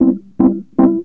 01 Rhodes 11.wav